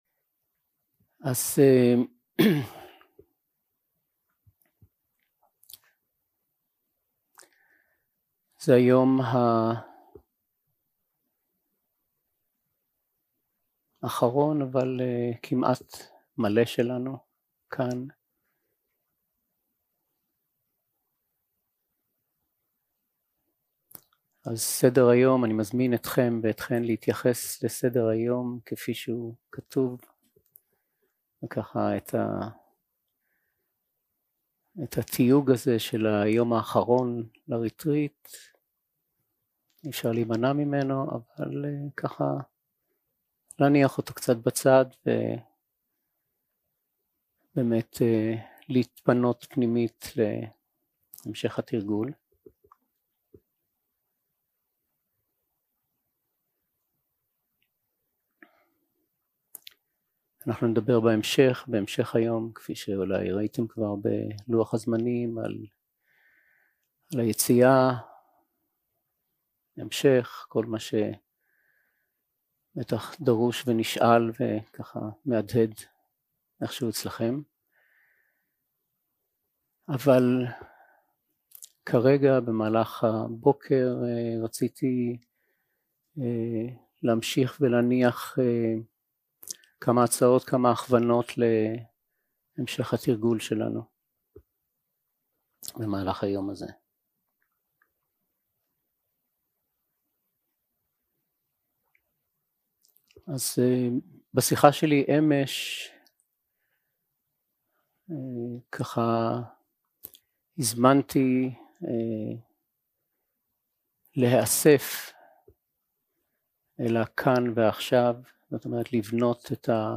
יום 4 – הקלטה 8 – בוקר – הנחיות למדיטציה – זה כך עכשיו
Guided meditation שפת ההקלטה